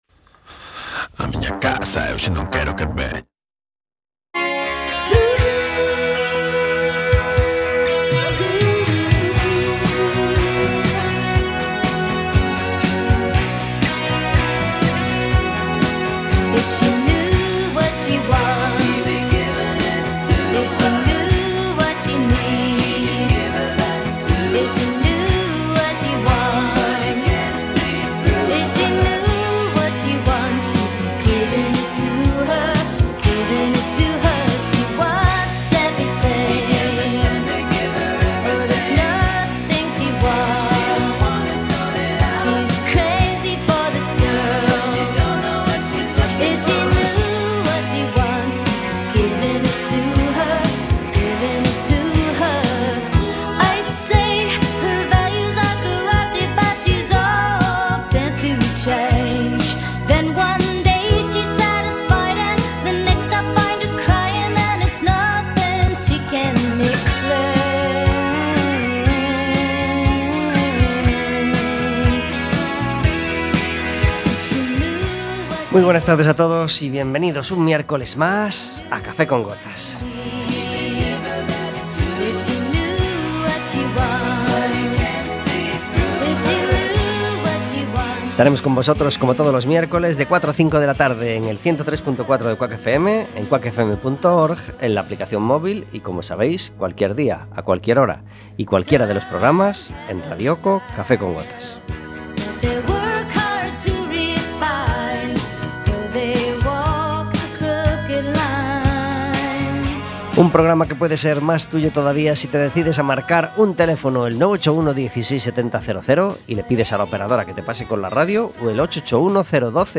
Un invitado cada día